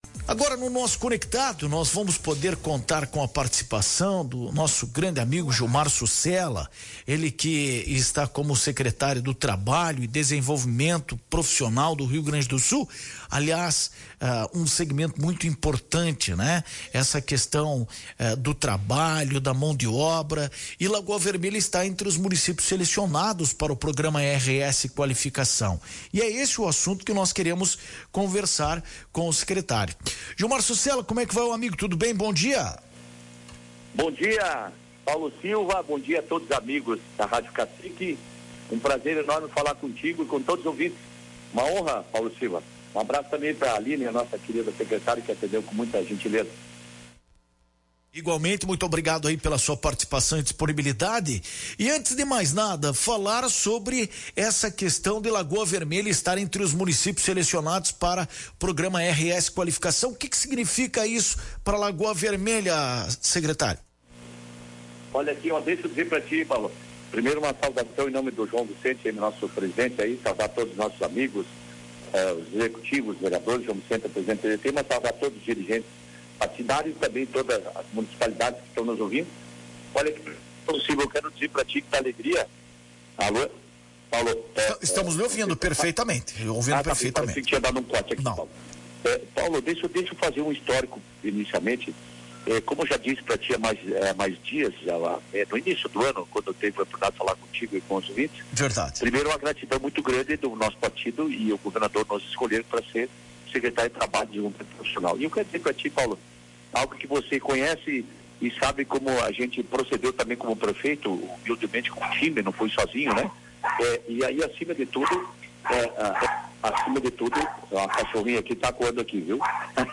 Na entrevista, Sossella explicou que a ideia por trás do programa é criar parcerias entre o governo estadual e as prefeituras, a fim de abranger todos os gaúchos.